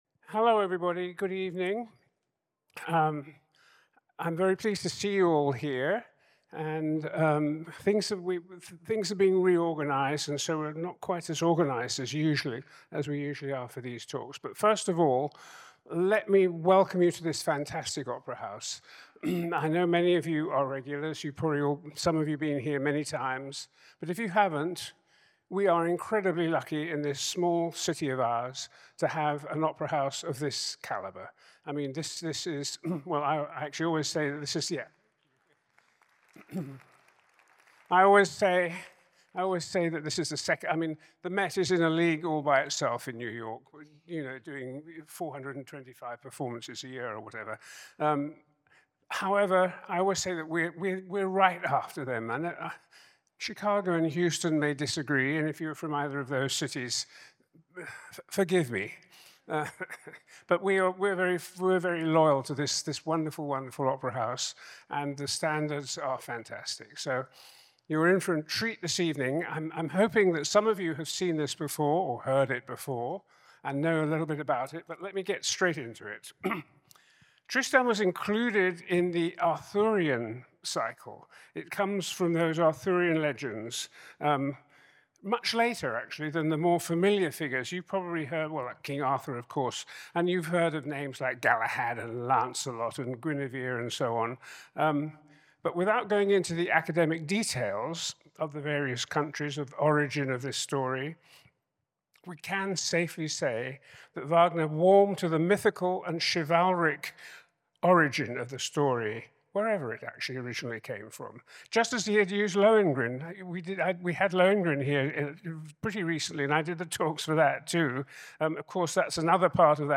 tristan_pre-show_lecture.mp3